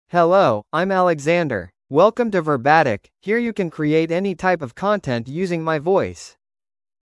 Alexander — Male English (United States) AI Voice | TTS, Voice Cloning & Video | Verbatik AI
MaleEnglish (United States)
Alexander is a male AI voice for English (United States).
Voice sample
Listen to Alexander's male English voice.